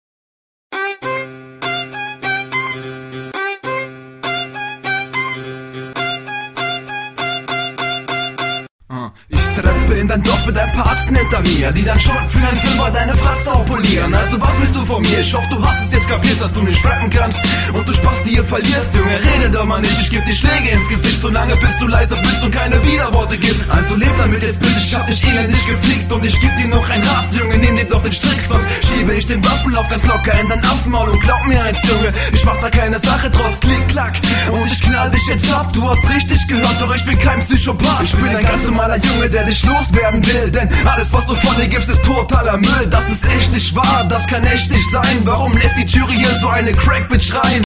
Runde ist deine beste bisher. Nicer Flow, schöne Betonung. Diese Runde ist mit Abstand die …
aggressive runde, der beat liegt dir, geht gut nach vorne, text nichts besonderes dabei bis …
Hasse den Beat. Hasse ihn über alles. Klingst aber ganz nett auf dem. Ist aber …